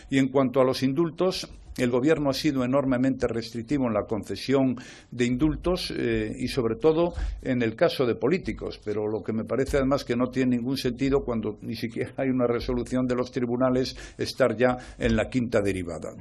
El presidente del Gobierno ha recordado en la conferencia de prensa que ha ofrecido al término del Consejo Europeo de Bruselas que ha sido muy restrictivo en la concesión de esta medida de gracia.